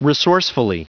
Prononciation du mot resourcefully en anglais (fichier audio)
Prononciation du mot : resourcefully